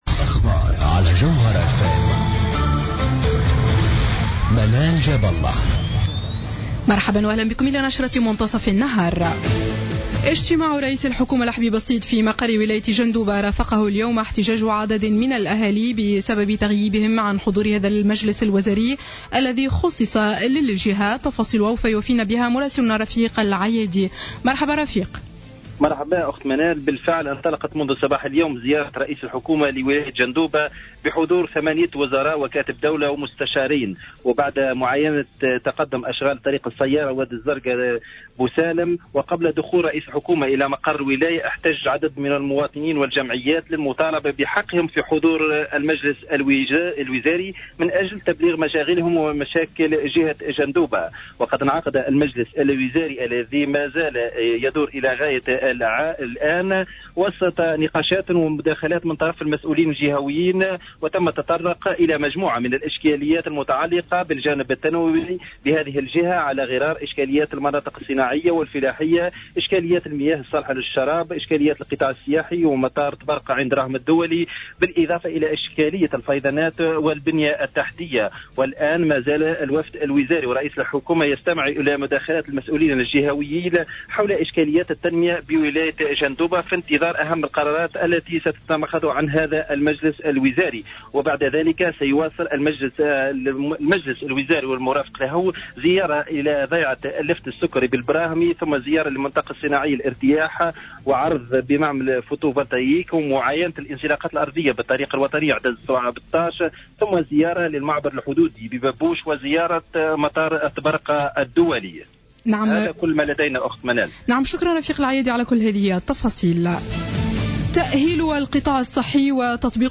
نشرة أخبار منتصف النهار ليوم السبت 25 أفريل 2015